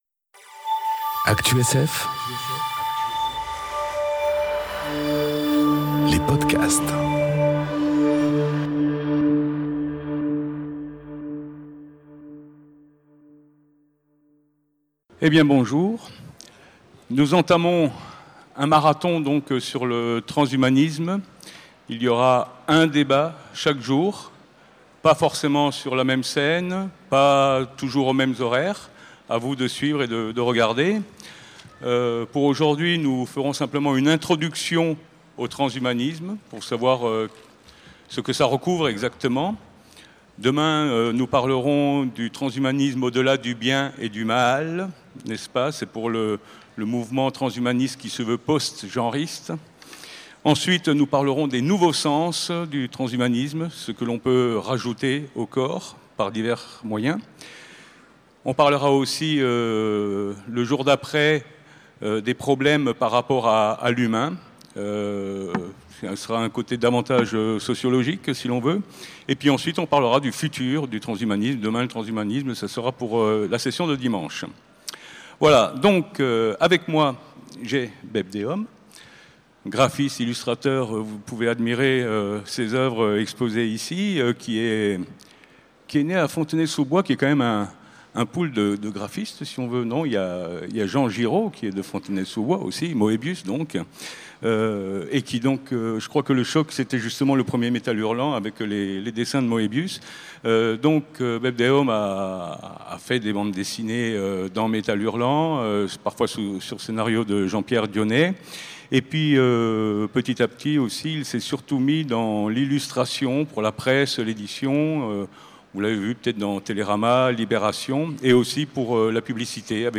Conférence Transhumanisme 1 : Introduction au Transhumanisme enregistrée aux Utopiales 2018